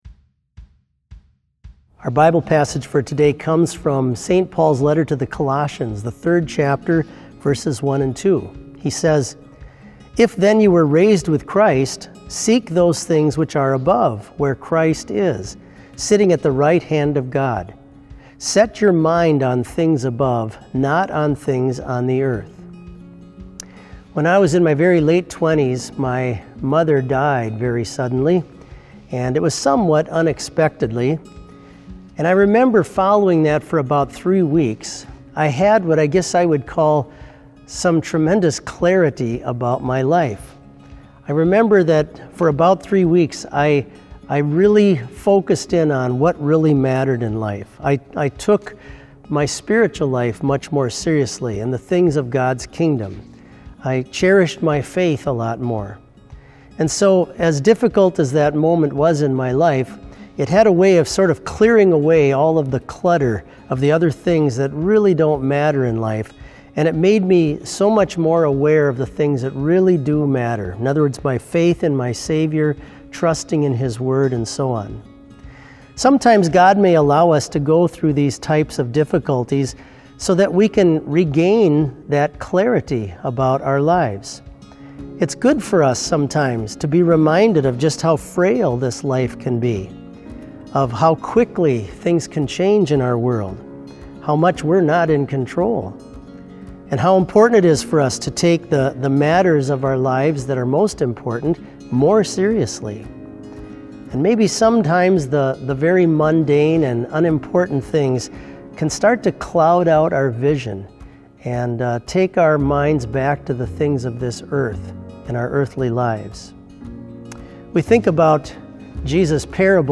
Complete service audio for BLC Devotion - March 18, 2020